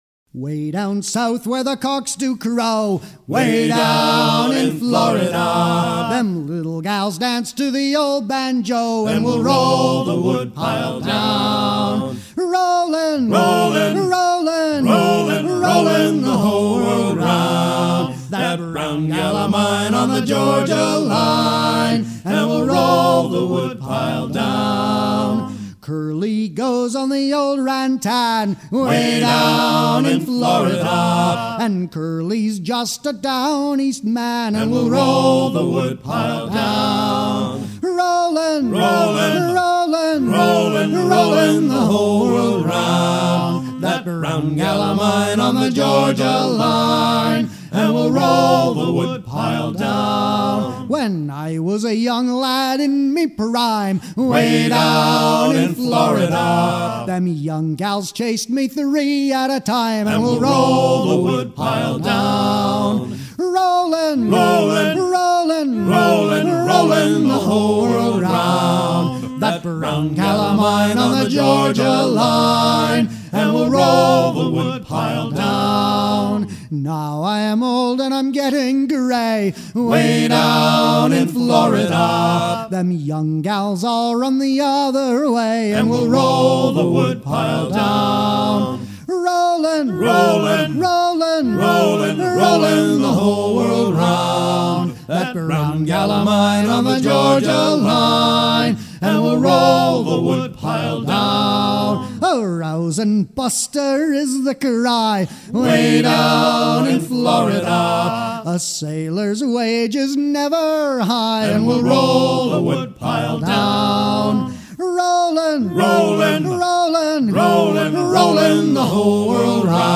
à virer au guindeau
Pièce musicale éditée